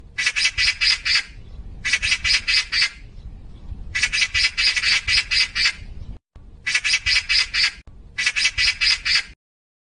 喜鹊叫声